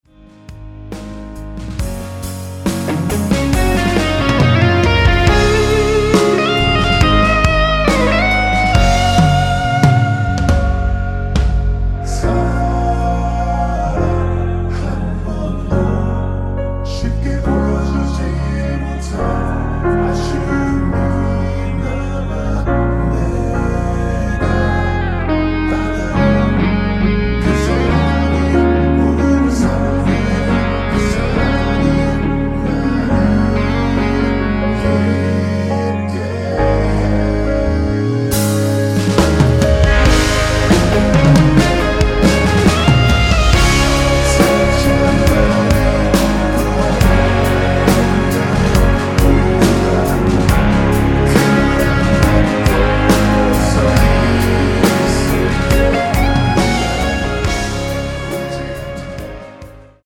원키에서(-3)내린 코러스 포함된 MR입니다.(미리듣기 확인)
Db
앞부분30초, 뒷부분30초씩 편집해서 올려 드리고 있습니다.
중간에 음이 끈어지고 다시 나오는 이유는